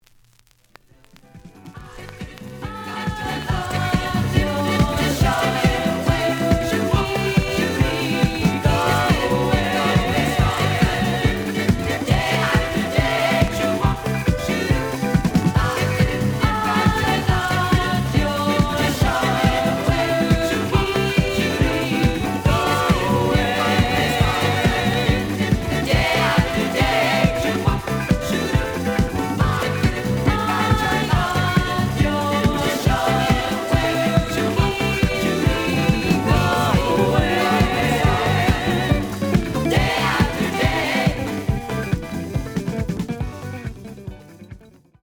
The audio sample is recorded from the actual item.
●Genre: Disco
Slight edge warp. But doesn't affect playing. Plays good.